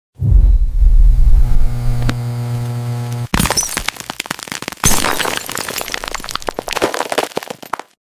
This is not your average chalk crush. This surreal AI ASMR features a metallic, robotic hand crushing a block of glassy chalk that shatters into crystal-like shards. The crisp, high-pitched glass-cracking sounds create an oddly satisfying and unforgettable sensory experience.